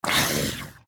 Minecraft Version Minecraft Version snapshot Latest Release | Latest Snapshot snapshot / assets / minecraft / sounds / mob / husk / hurt2.ogg Compare With Compare With Latest Release | Latest Snapshot
hurt2.ogg